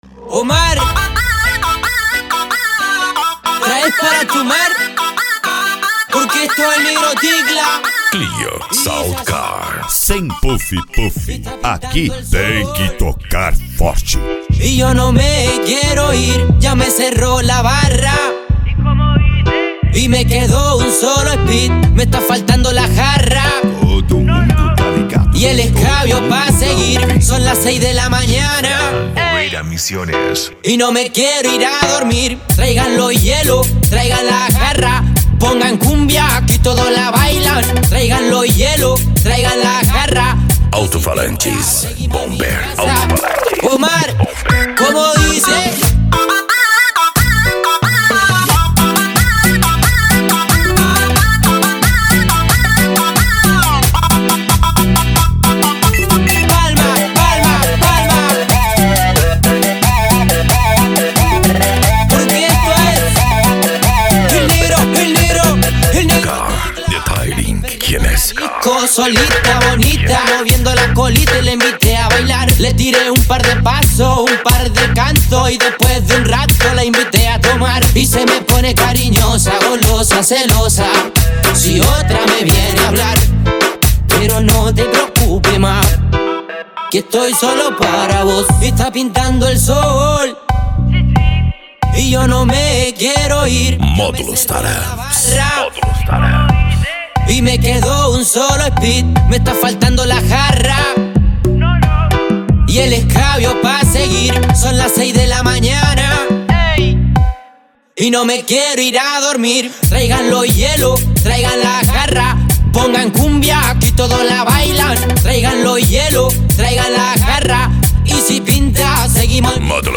Bass
Eletronica
Modao
Remix